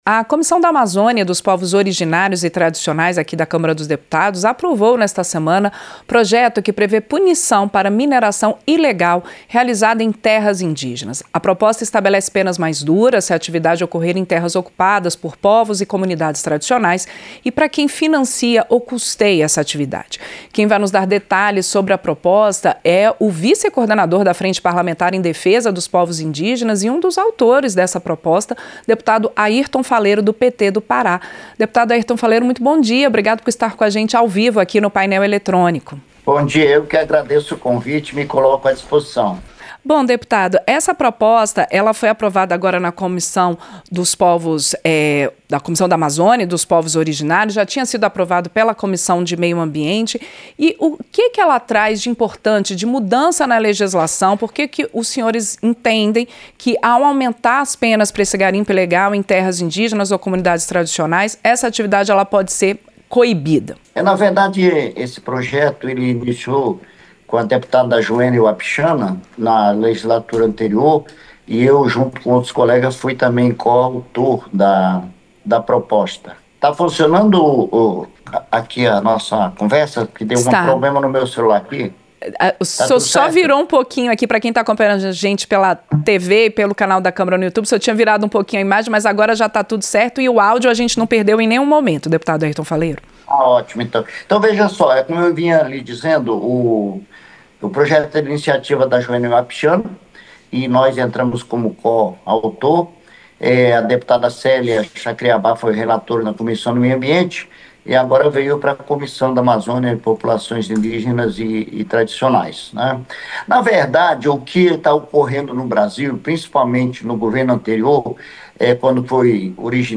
Entrevista - Dep. Airton Faleiro (PT-PA)